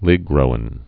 (lĭgrō-ĭn)